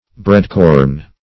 Breadcorn \Bread"corn`\ Corn of grain of which bread is made, as wheat, rye, etc.